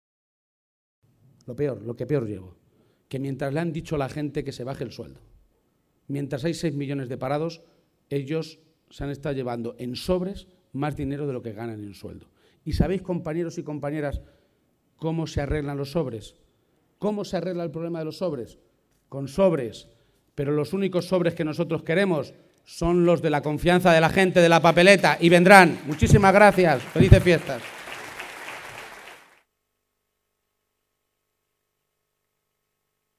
El secretario general del PSCM-PSOE presidió la tradicional comida navideña con militantes socialistas de Albacete
Audio Page Comida Navidad Albacete_211213